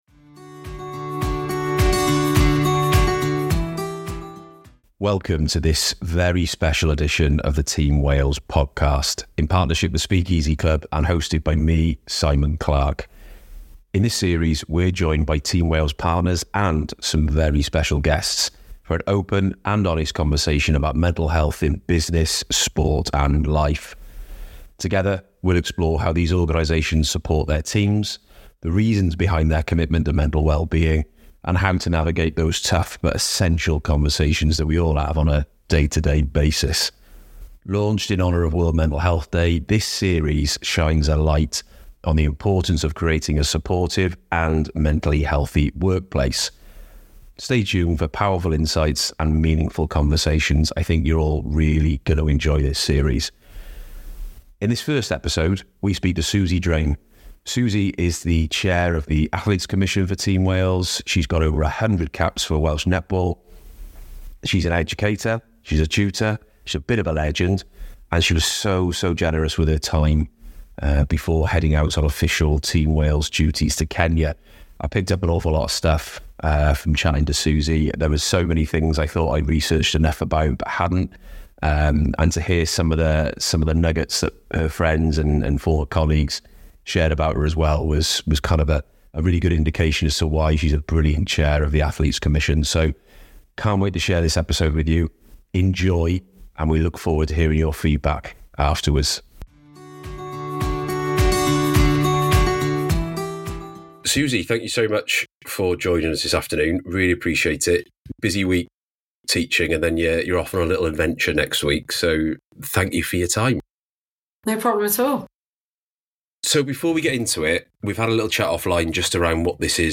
In this series, we’re joined by Team Wales partners and some very special guests for an open and honest conversation about mental health in business, sport and life. We’ll explore how these organizations support their teams, the reasons behind their commitment to mental well-being, and how to navigate those tough but essential conversations. Launched in honour of World Mental Health Day, this series shines a light on the importance of creating a supportive and mentally healthy workplace.